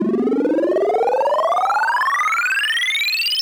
MB Trans FX (1).wav